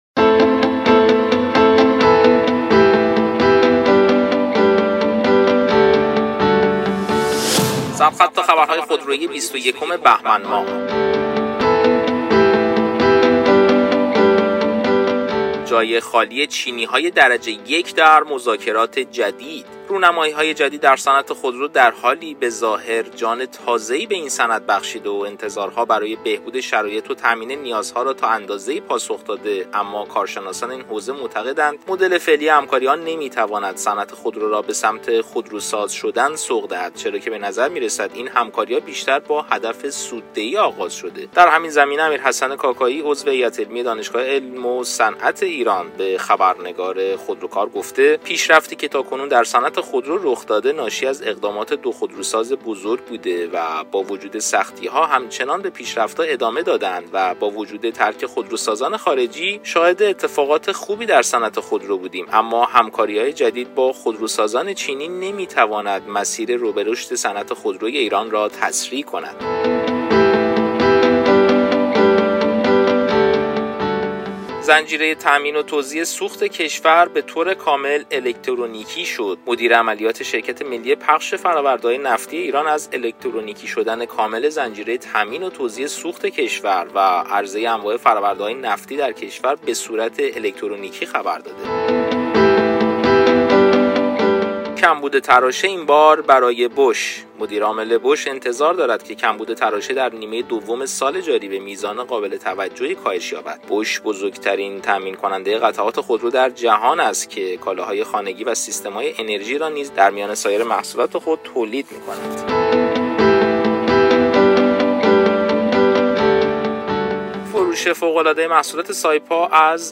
برچسب ها: سرخط خبرهای خودرویی ، اخبار مهم خودرویی ، تیتر اخبار خودرویی ، اخبار خودرویی صوتی